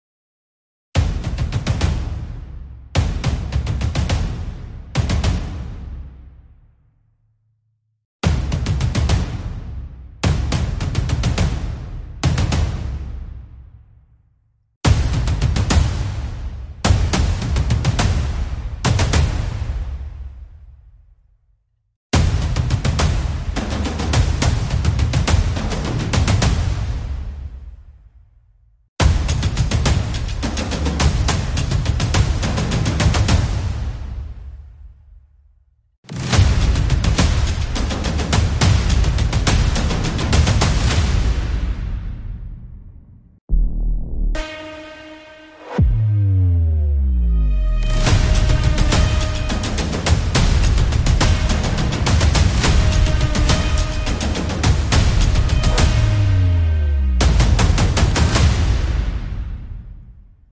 HOW TO EPIC DRUMS IN Sound Effects Free Download